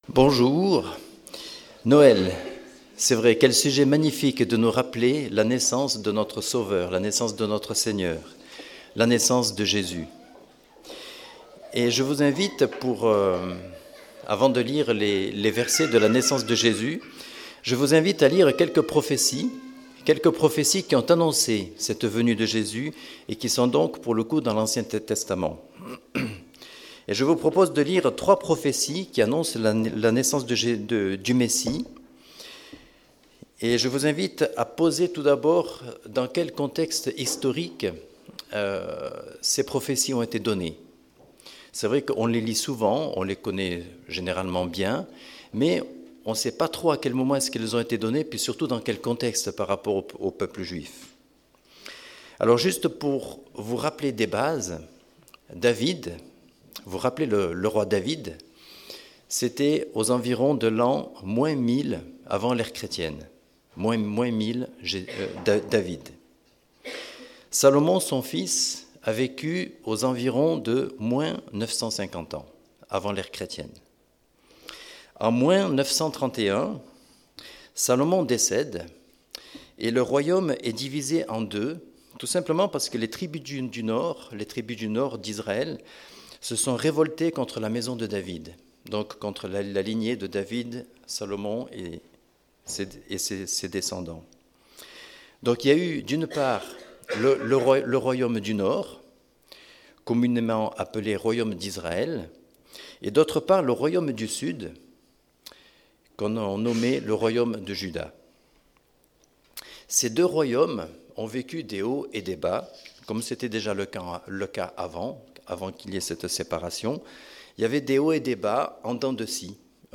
Culte de Noël du 22 décembre